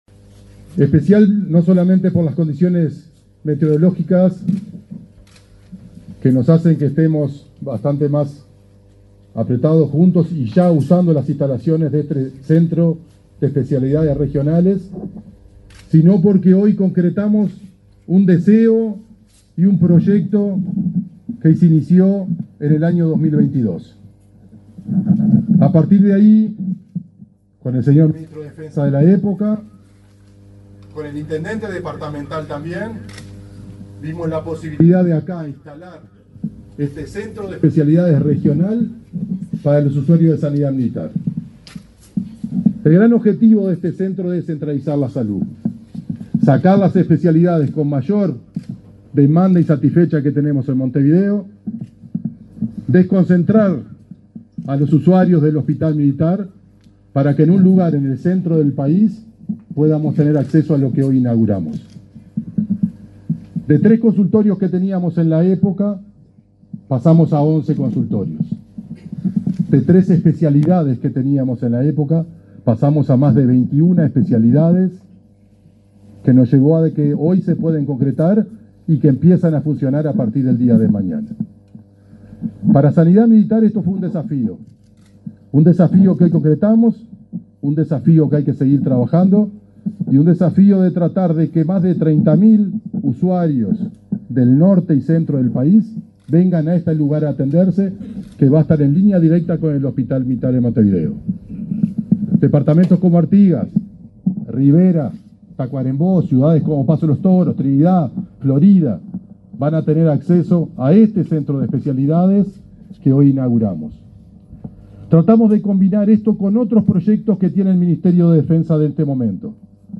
Palabras de autoridades en acto en Durazno
Palabras de autoridades en acto en Durazno 11/09/2024 Compartir Facebook X Copiar enlace WhatsApp LinkedIn El director nacional de Sanidad de las Fuerzas Armadas, Hugo Rebollo; el intendente de Durazno, Carmelo Vidalín, y el ministro de Defensa, Armando Castaingdebat, participaron en la inauguración del Centro de Especialidades Regional n.° 13, en el hospital militar de Durazno.